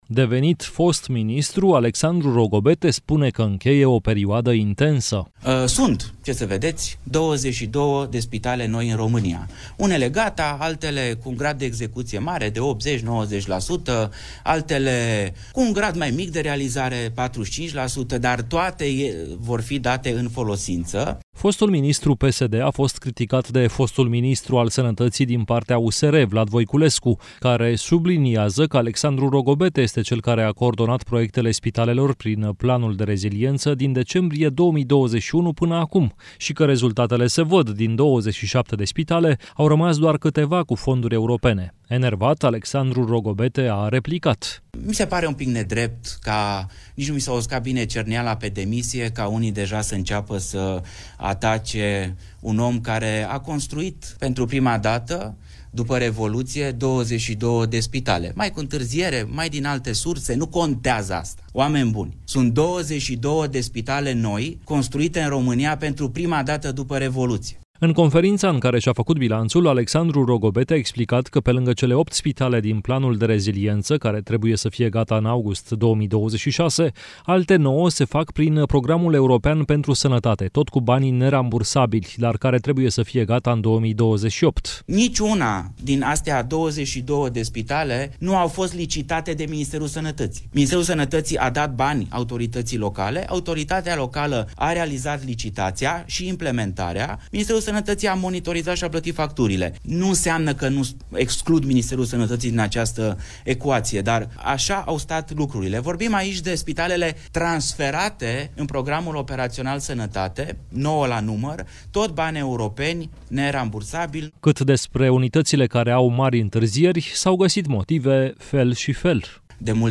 În conferința în care și-a făcut bilanțul, Alexandru Rogobete a explicat că, pe lângă cele opt spitale din Planul de Redresare și Reziliență, care trebuie să fie gata în august 2026, alte nouă se fac prin Programul European pentru Sănătate, tot cu bani nerambursabili, dar care trebuie să fie gata în 2028.